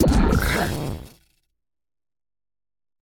Cri d'Ampibidou dans Pokémon Écarlate et Violet.